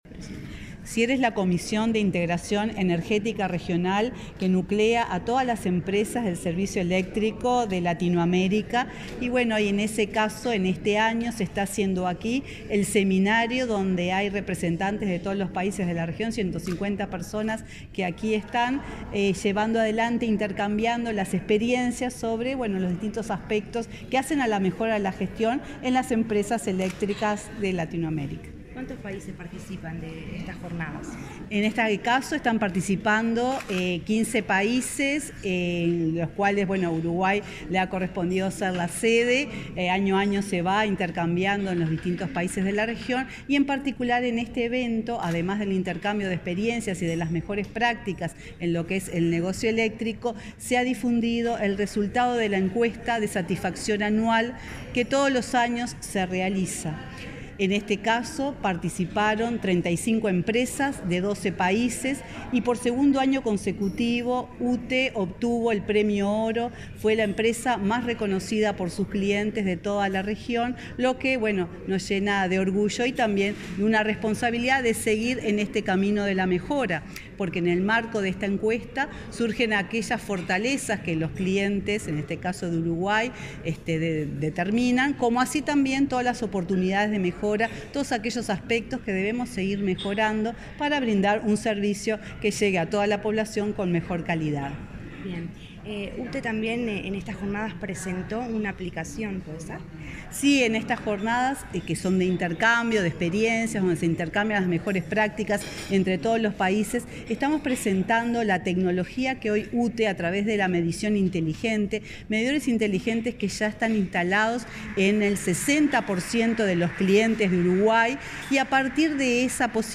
Entrevista a la presidenta de UTE
La presidenta de UTE, Silvia Emaldi, dialogó con Comunicación Presidencial antes de participar en el seminario Caminos para la Excelencia en